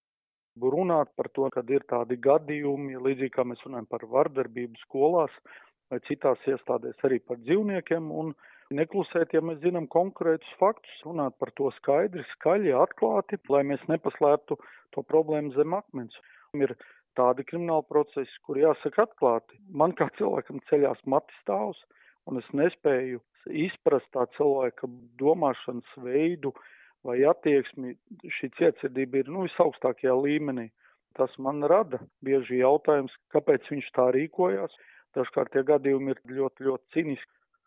Zemgales tiesas apgabala prokuratūras virsprokurors Aigars Bičušs